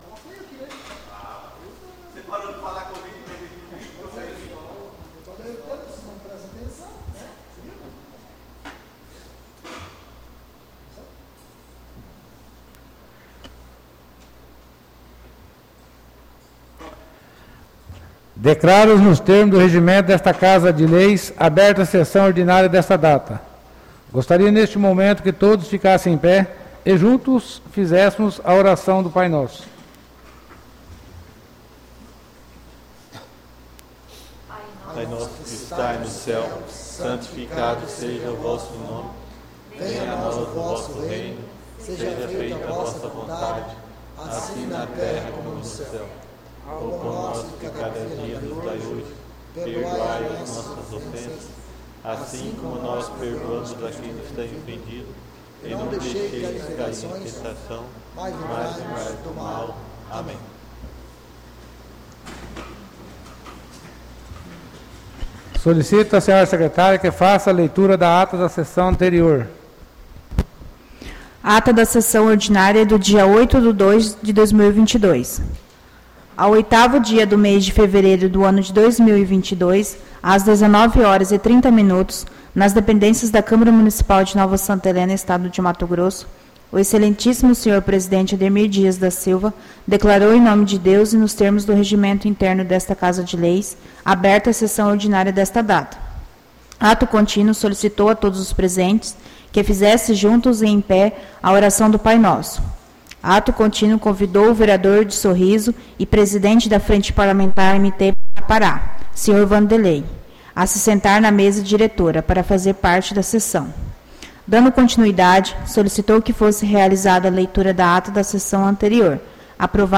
Sessões Plenárias